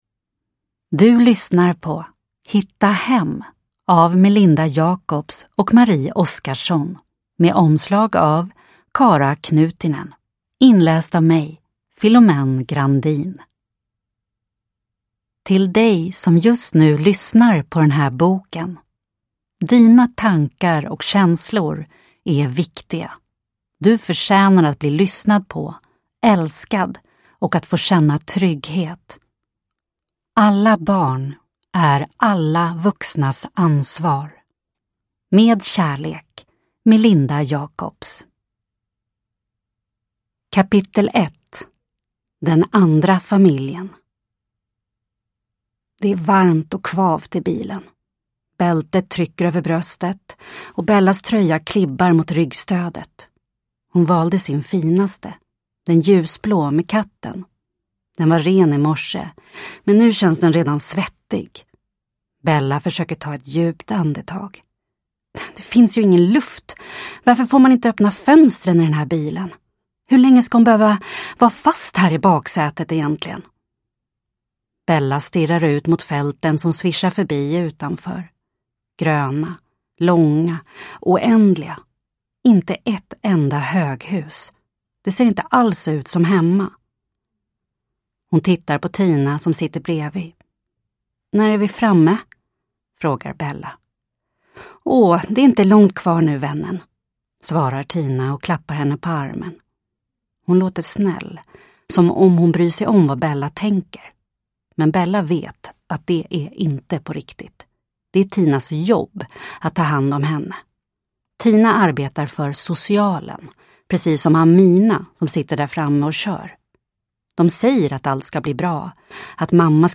Hitta hem – Ljudbok – Laddas ner